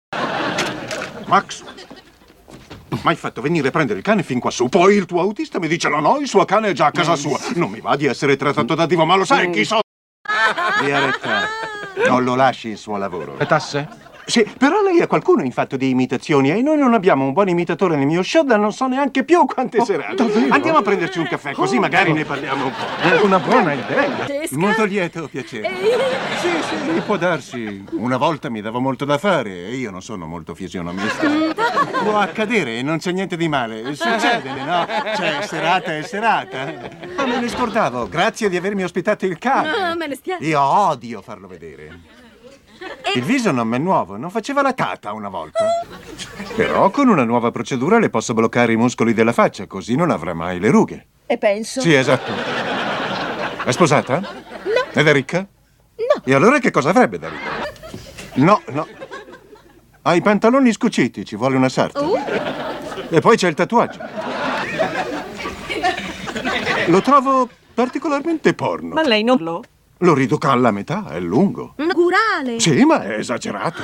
telefilm "La Tata", in cui doppia le guest-star Jay Leno e John Astin.